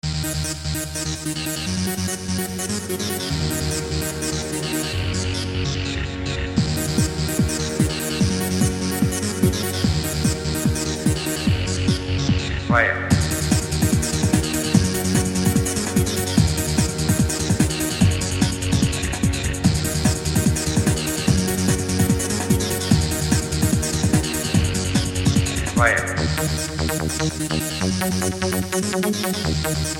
Digital Stereo Techno-Rave Cyber-Delic Audio Sound Tracks